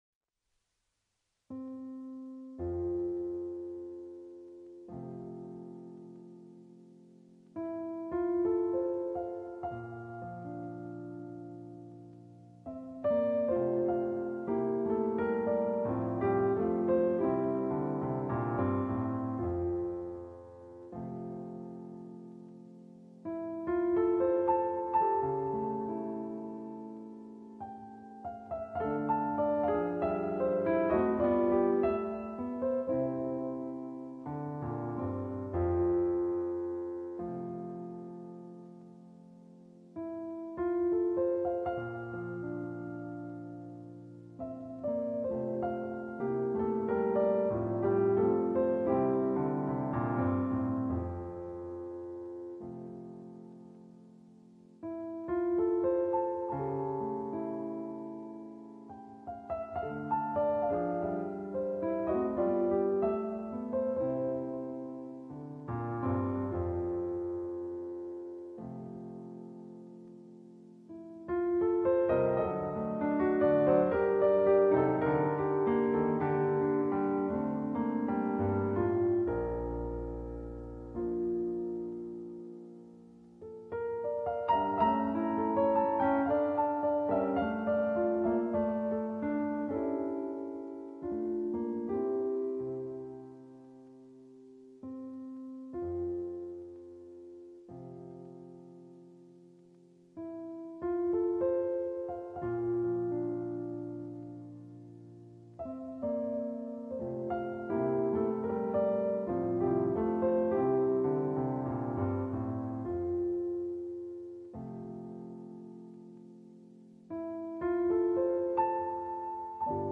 (da mein Klavier derzeit etwas verstimmt ist, hab ich’s elektronisch eingespielt)